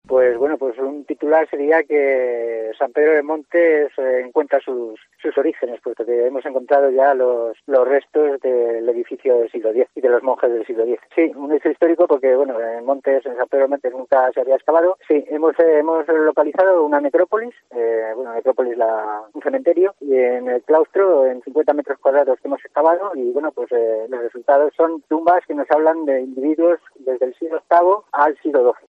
Escucha aquí al historiador